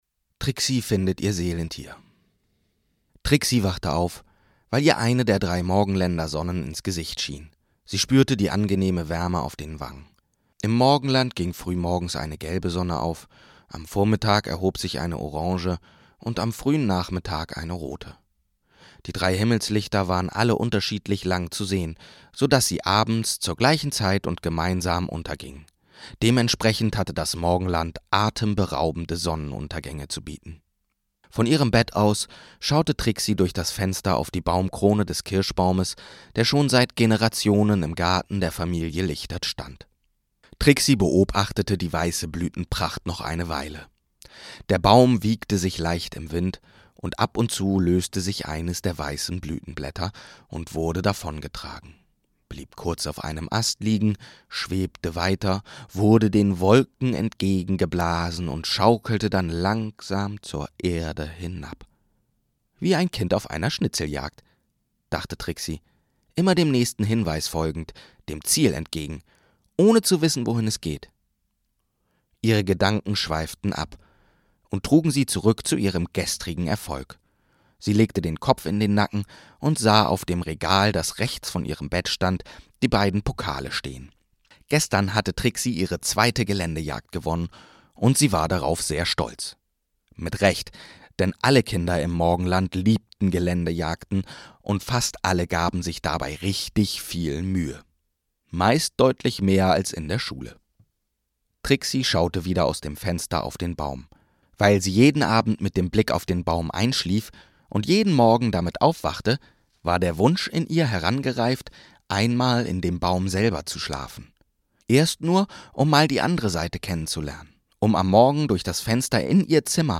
H&oumlrspiele aus der Werkstatt